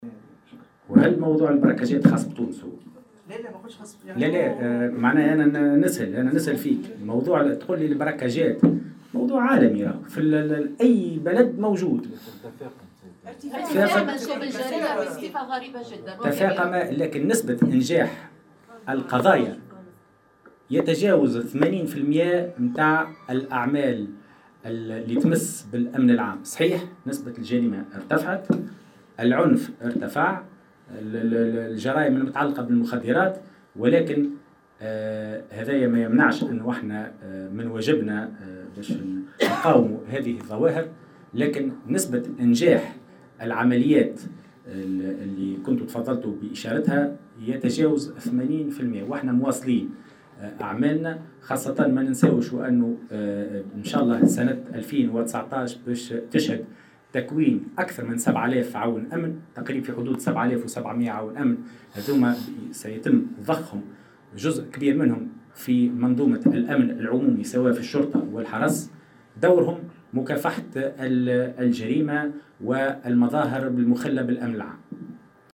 وأقر وزير الداخلية خلال ندوة صحفية عقب جلسة استماع مغلقة له بلجنة الأمن والدفاع اليوم بمجلس نواب الشعب، بتفاقم هذه الظاهرة الجريمة عموما في تونس مشددا على ضرورة مكافحتها.